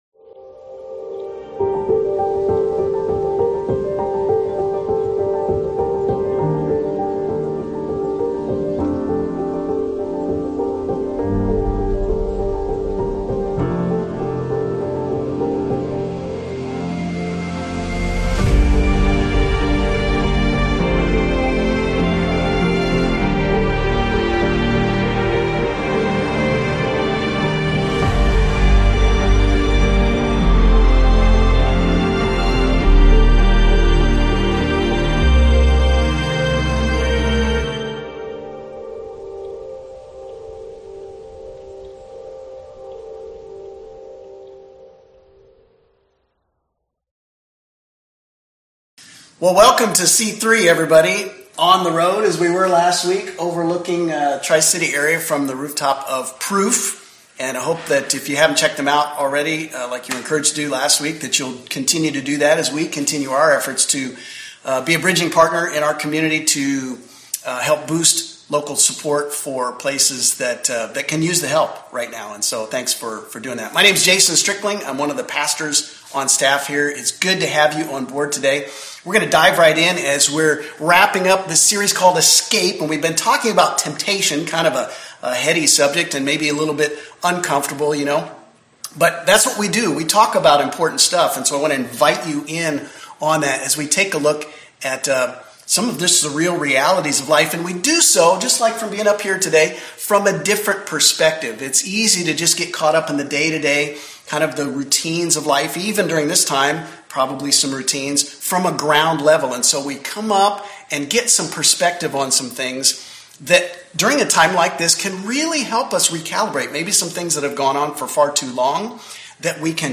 A message from the series "Take Back Your Life."